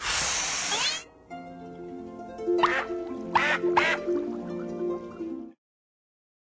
bubbleblow.ogg